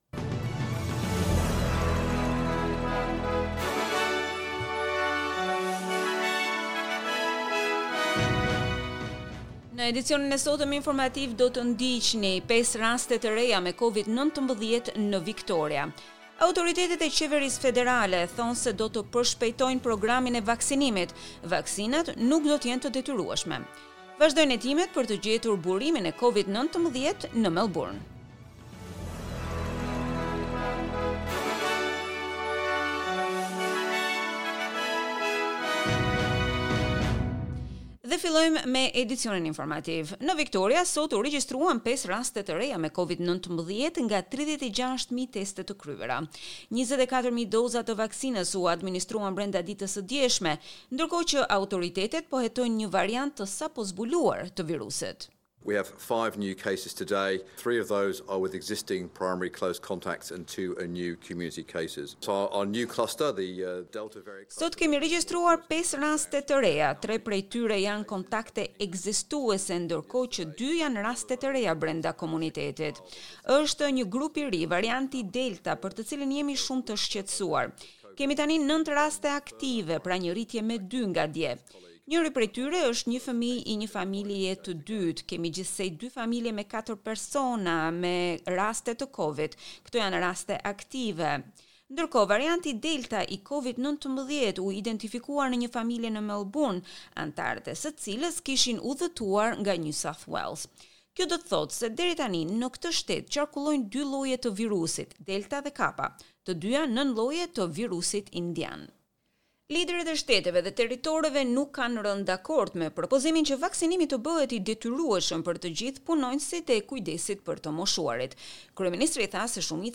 SBS News Bulletin in Albanian - 5 June 2021